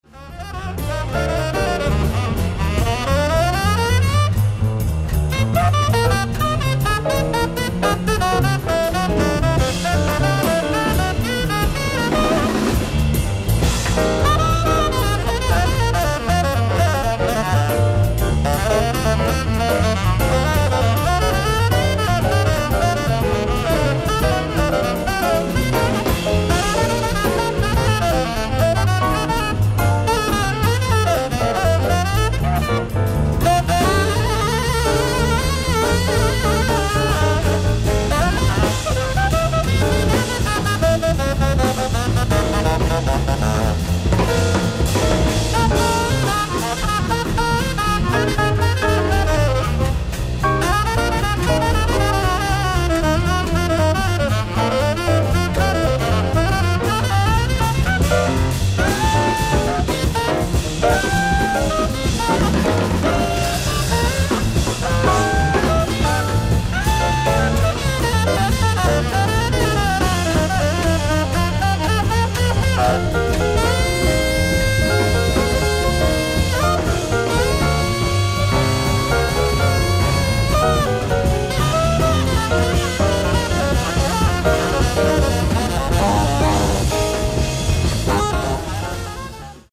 ライブ・アット・ジャズ・イン・マルシアック、マルシアック、フランス
※試聴用に実際より音質を落としています。